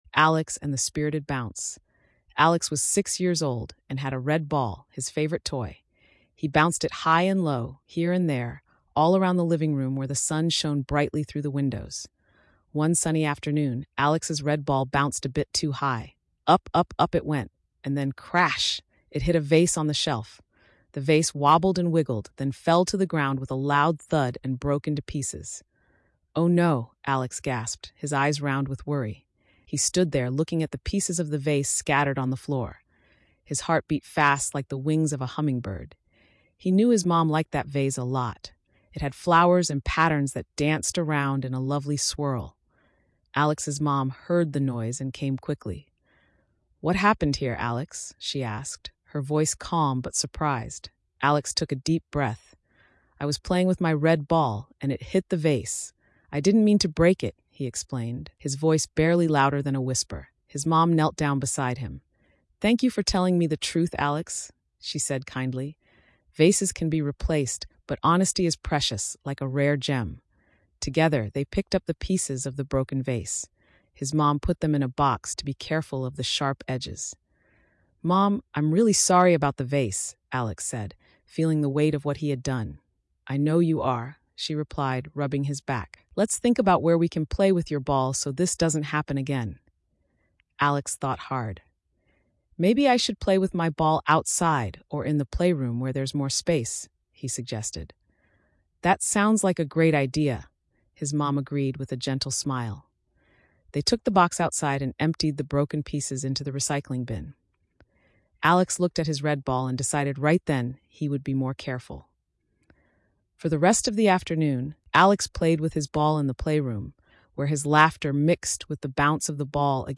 You can also generate an audio version of your story
Here is an example of a story generated by StorySprout.